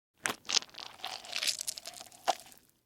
Melon Rip Apart
SFX
yt_C1PA7mIi2j8_melon_rip_apart.mp3